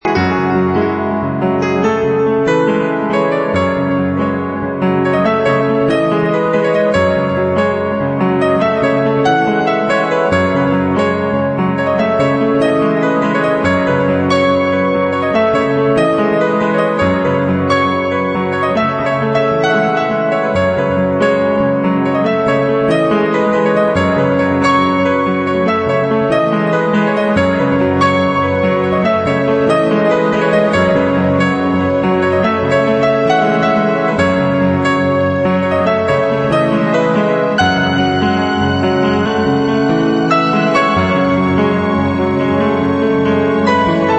Klavírne sólo